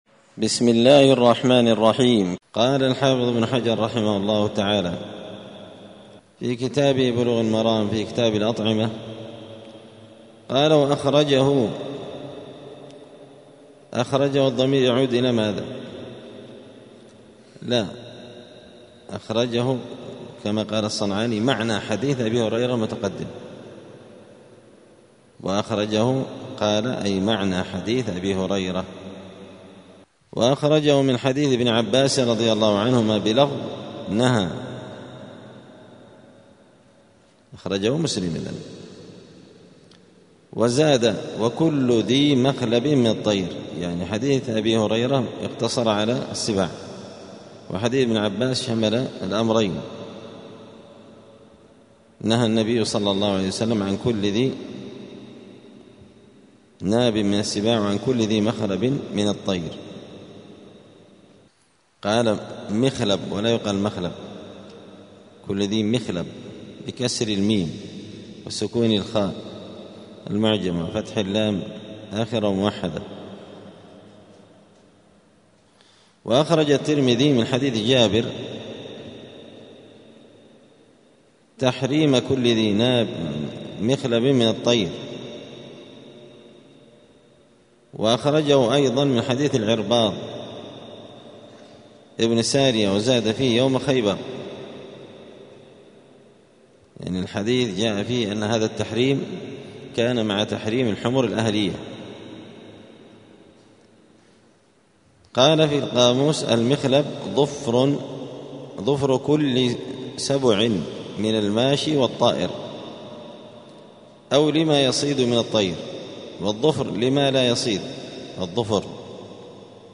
*الدرس الثاني (2) {حكم أكل لحوم الحمر الأهلية}*